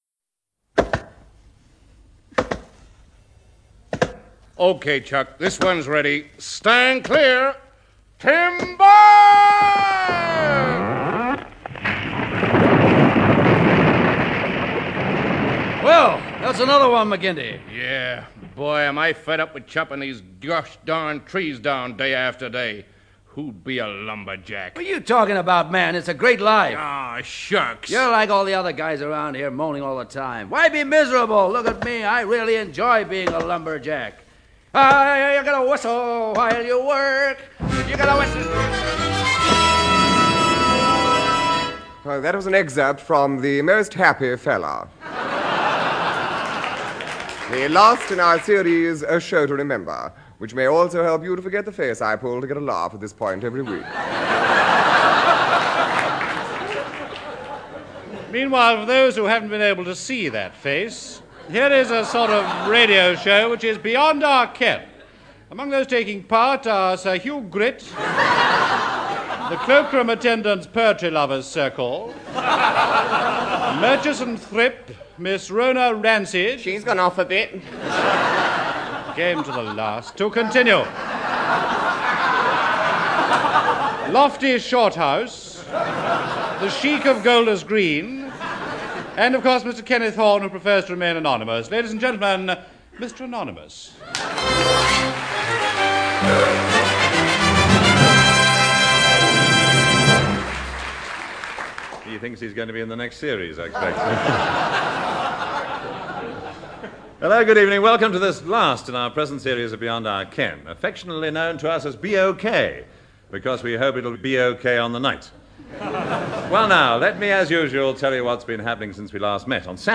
An archive of the radio comedy show Beyond Our Ken